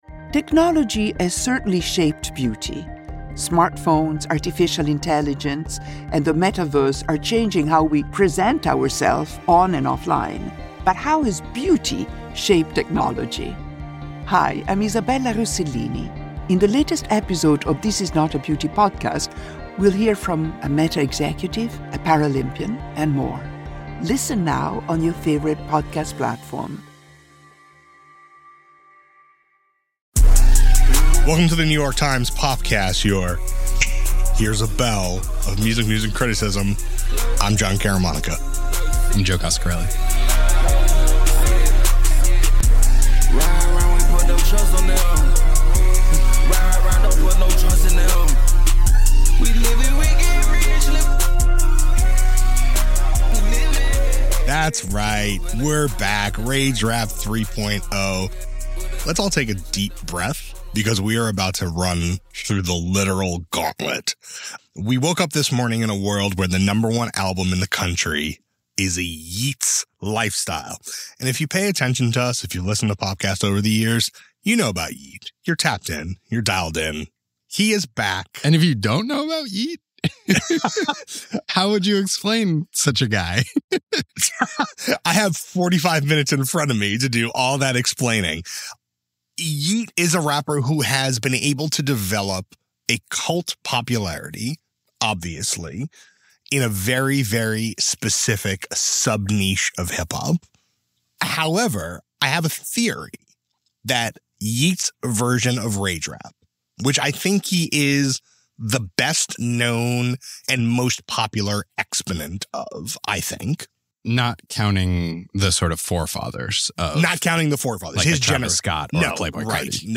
A conversation about the mysterious rapper’s rise and the impact of hip-hop’s splintering.